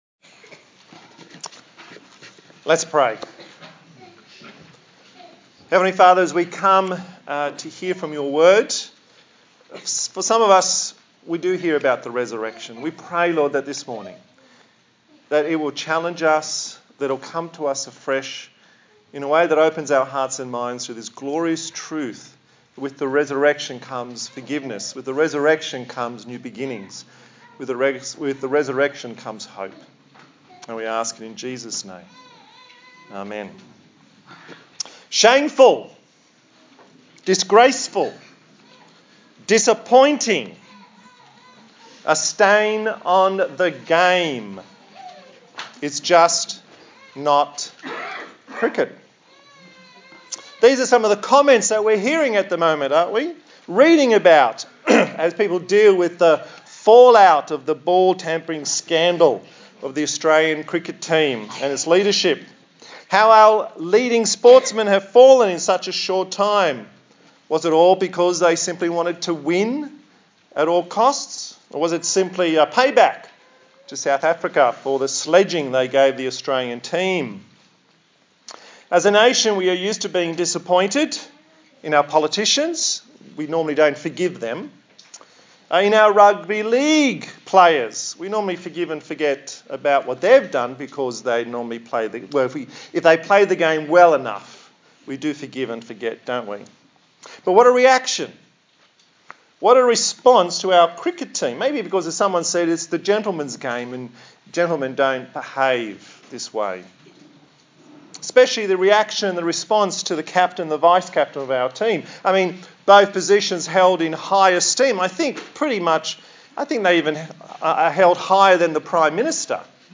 Service Type: Easter Sunday